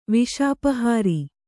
♪ viṣāpahāri